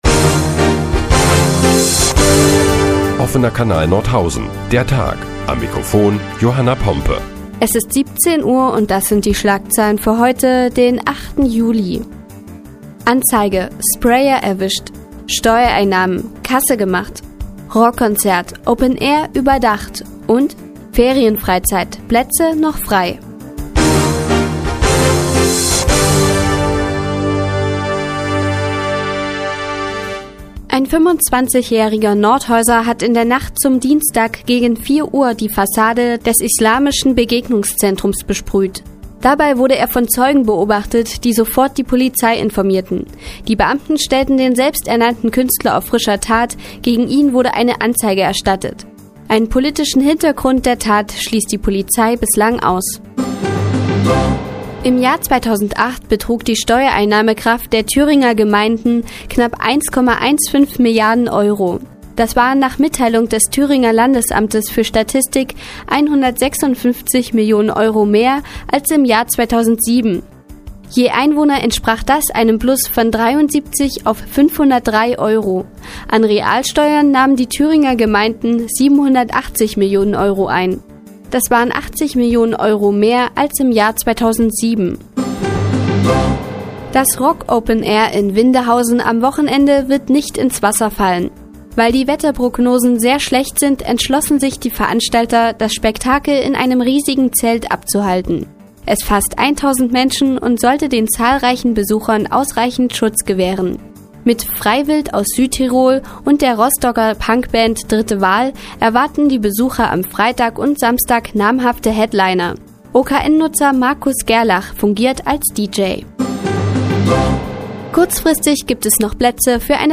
Die tägliche Nachrichtensendung des OKN ist nun auch in der nnz zu hören. Heute geht es unter anderem um eine Anzeige gegen einen Nordhäuser Sprayer und Steuereinnahmen der Thüringer Gemeinden.